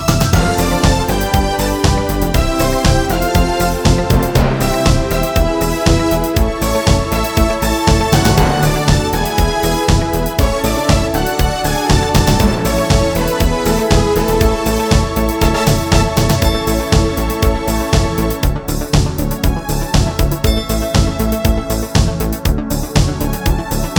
Key of B Major Pop (1980s) 3:16 Buy £1.50